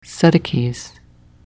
Sadoques pronunciation only